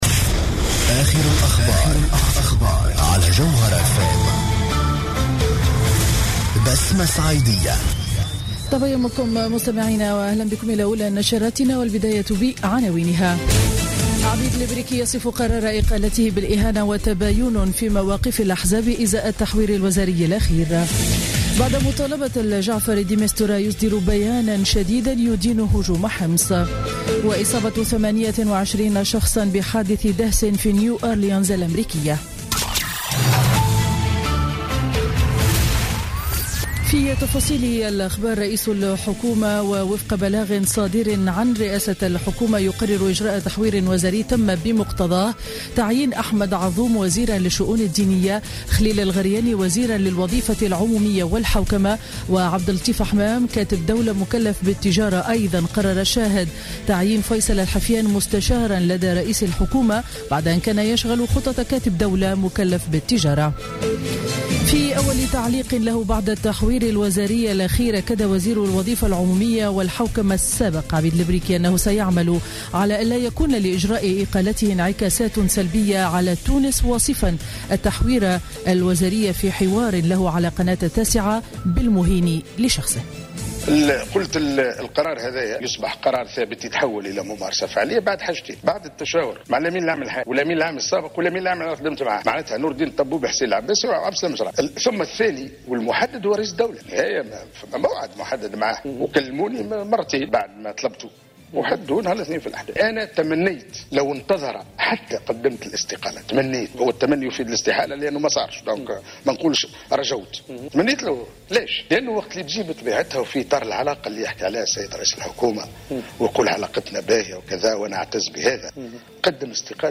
نشرة أخبار السابعة صباحا ليوم الأحد 26 فيفري 2017